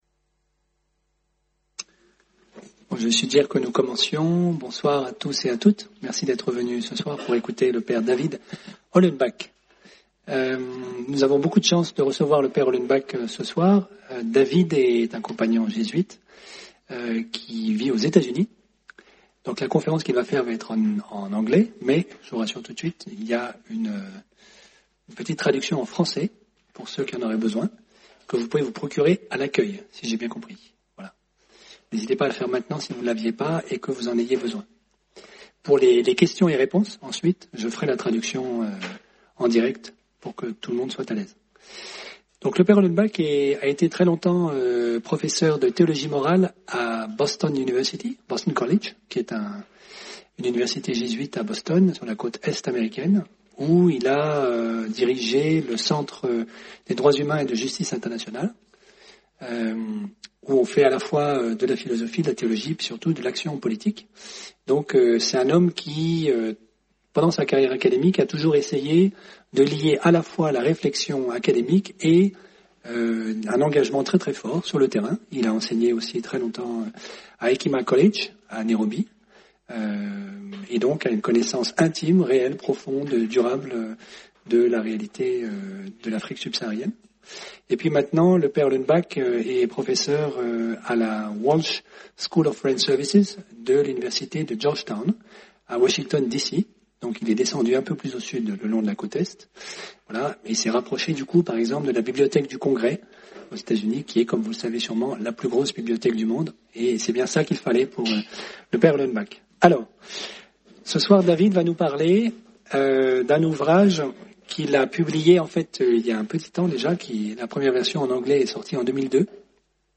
Soirée rencontre du 5 mars 2019.